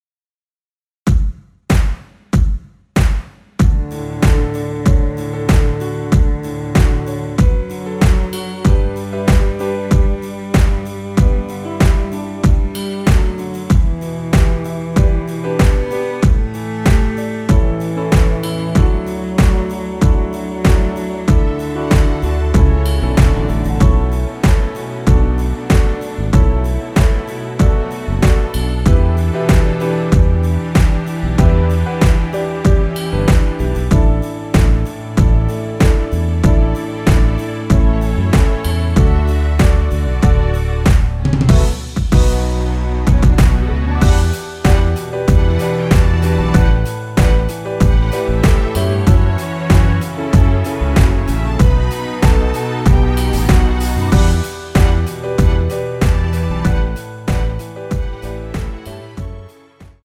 엔딩이 페이드 아웃이라 엔딩을 만들어 놓았습니다.
앞부분30초, 뒷부분30초씩 편집해서 올려 드리고 있습니다.
중간에 음이 끈어지고 다시 나오는 이유는